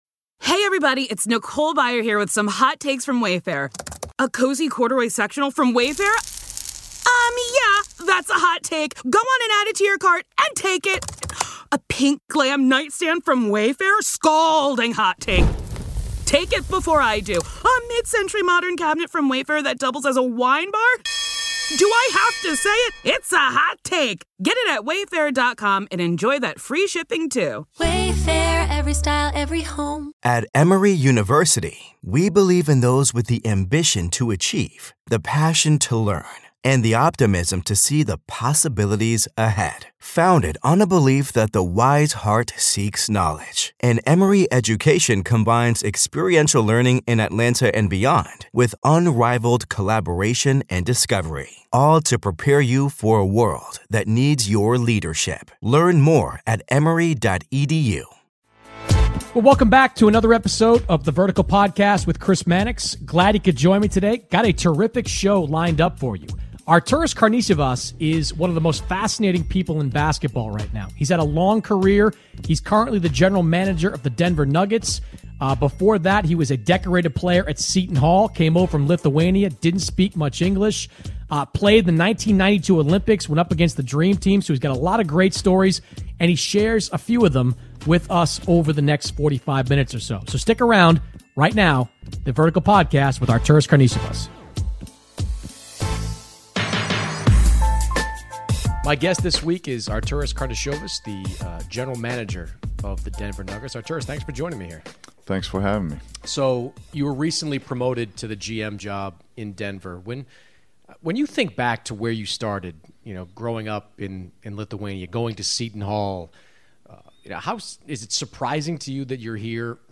Arturas Karnisovas joins the podcast The Crossover NBA Show SI NBA Basketball, Sports 4.6 • 641 Ratings 🗓 9 August 2017 ⏱ 49 minutes 🔗 Recording | iTunes | RSS 🧾 Download transcript Summary Joining Chris Mannix on The Vertical this week is the general manager of the Denver Nuggets; Arturas Karnisovas. Chris talks with Arturas about playing against the Dream Team, international players in the NBA and bringing Paul Millsap to Denver.